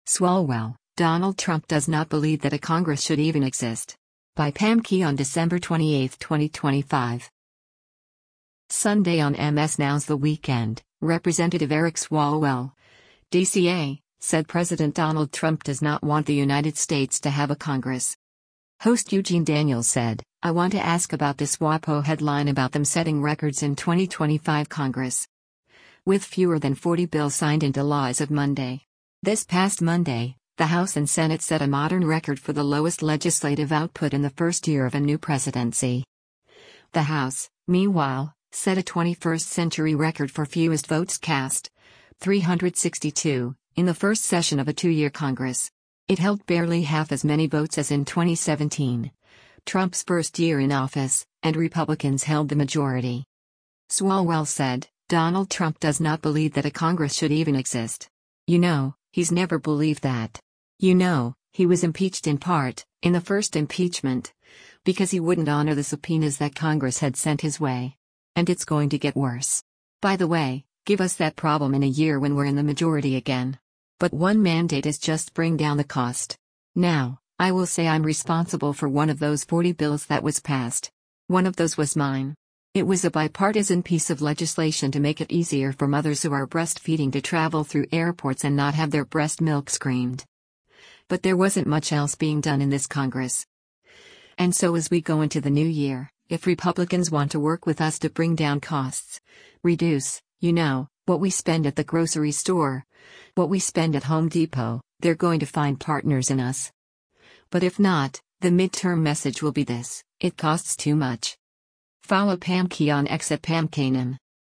Sunday on MS NOW’s “The Weekend,” Rep. Eric Swalwell (D-CA) said President Donald Trump does not want the United States to have a Congress.